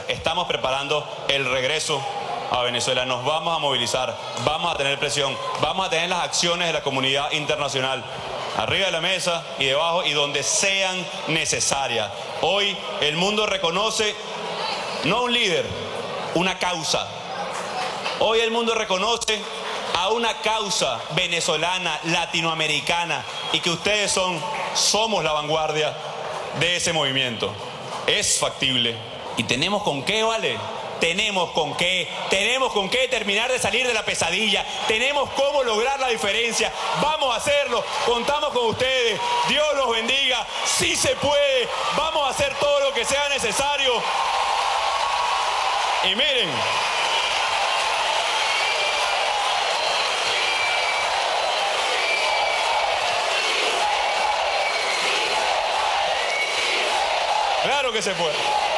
"Estamos dispuestos a echar el resto en esta lucha. La única limitación que yo tengo es mi vida, y la he puesto a disposición de esta causa, como el resto de los que hoy me acompañan aquí", afirmó el presidente encargado de Venezuela Juan Guaidó durante un encuentro con las comunidades latinas en Miami, celebrado en el Miami Airport Convention Center, el sábado.
Palabras del presidente encargado de Venezuela Juan Guaidó en Miami